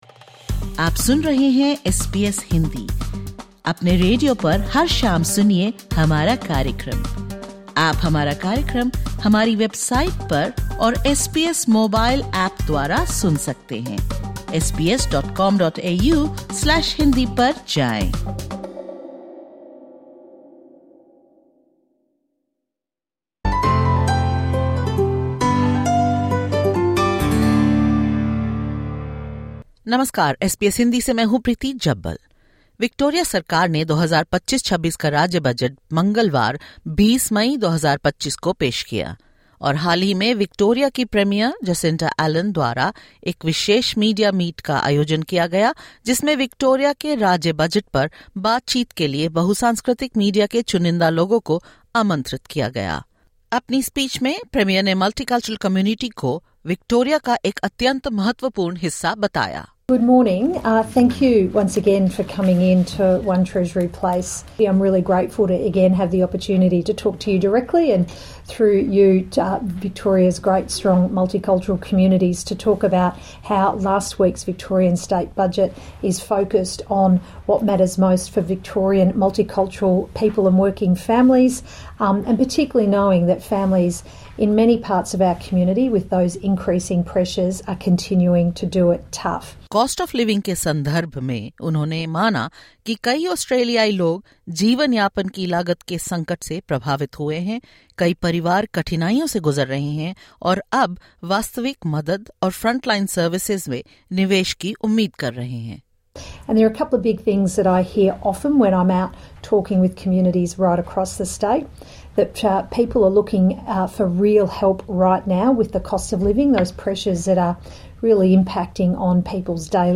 Victoria’s Premier Jacinta Allan recently held a media conference outlining key points of the state’s multicultural budget, addressing cost of living, community safety, small business support, and services for culturally and linguistically diverse (CALD) communities. She also responded to questions from multicultural media representatives on issues impacting their communities.